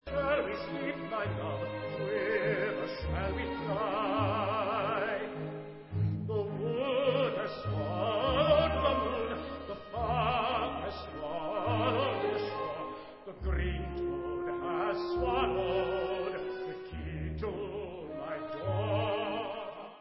Recording: OPERA